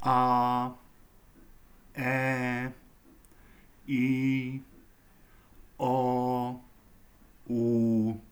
samohlasky.wav